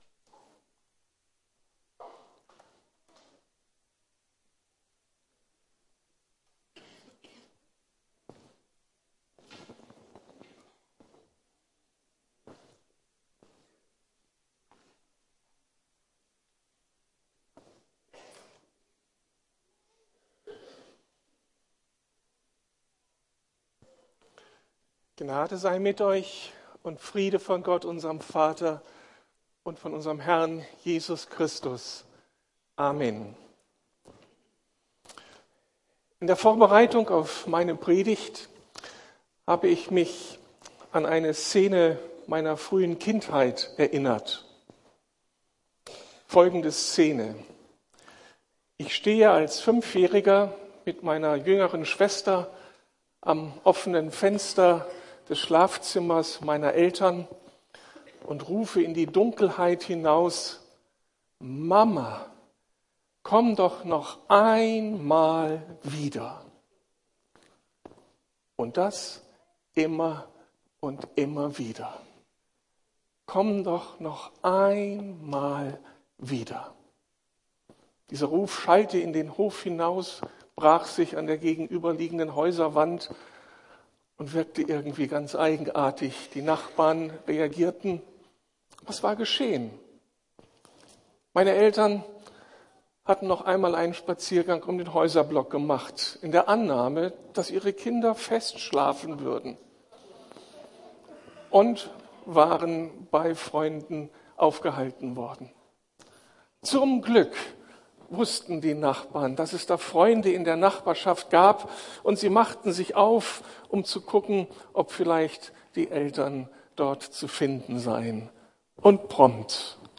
Advent - Eine Zeit der Geborgenheit ~ Predigten der LUKAS GEMEINDE Podcast